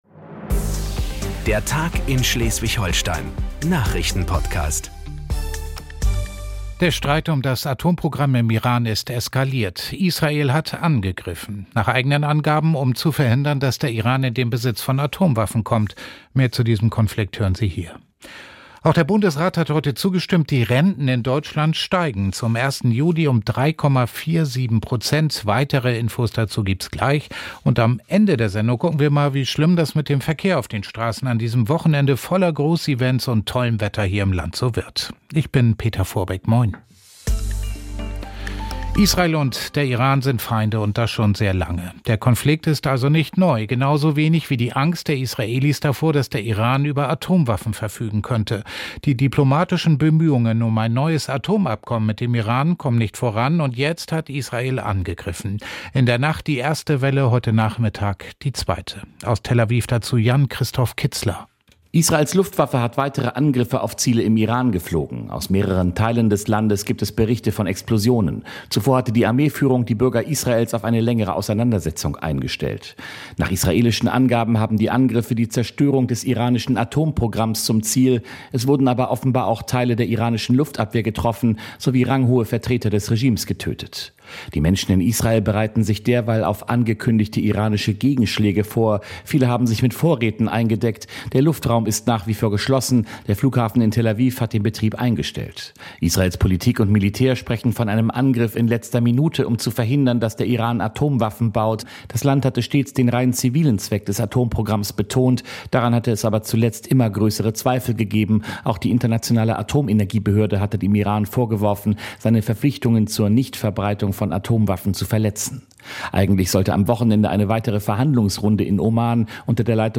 Die wichtigsten Nachrichten und Hintergründe liefert unser Podcast frei Haus. Reporter*innen aus unseren Studios in Flensburg, Heide, Norderstedt, Lübeck und Kiel sind in allen Regionen unterwegs und immer dicht dran - an den Themen des Tages in Schleswig-Holstein. Wir bieten Orientierung in der Flut von Nachrichten, ordnen ein und lassen Expert*innen einschätzen.
… continue reading 63 에피소드 # Tägliche Nachrichten # Nachrichten # NDR 1 Welle Nord